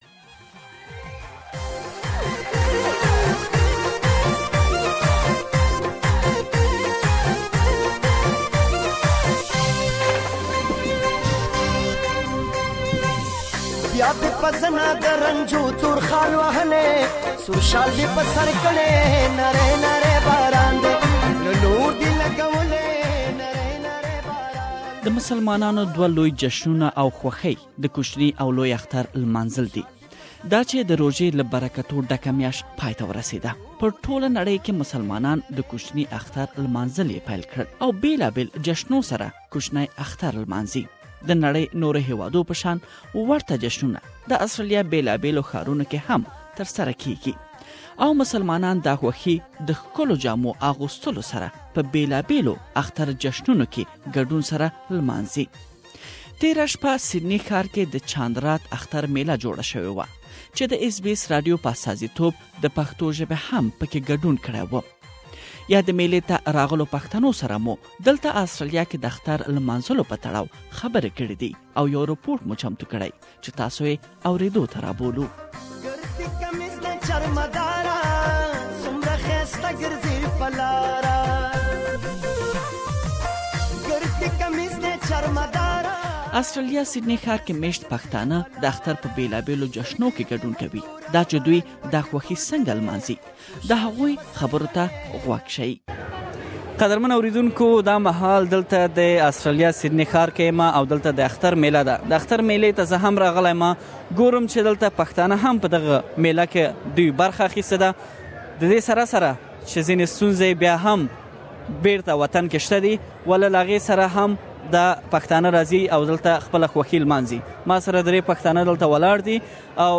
Sydney was the host to one of the biggest multi-cultural Eid festival Chand Raat, where thousands have participated. SBS Pashto was present at the festival and brought to you the voices of Pashtun community members where they were celebrating Eid in Australia. We have prepared a special report on Eid Festival in Sydney that you can listen to it here.